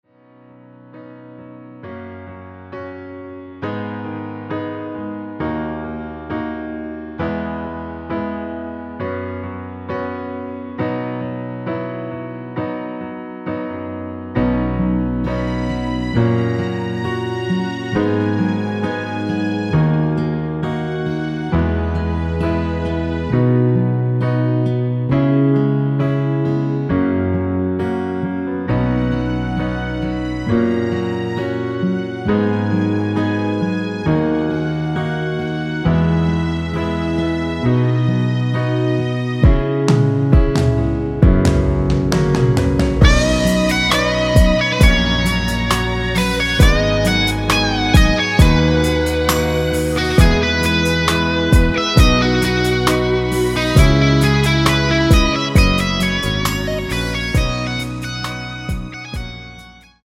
1절후 후렴으로 편곡한 MR (진행 순서는 아래의 가사와 미리듣기 참조 하세요)
엔딩이 페이드 아웃이라 노래 하시기 좋게 엔딩을 만들어 놓았습니다.
◈ 곡명 옆 (-1)은 반음 내림, (+1)은 반음 올림 입니다.
앞부분30초, 뒷부분30초씩 편집해서 올려 드리고 있습니다.
중간에 음이 끈어지고 다시 나오는 이유는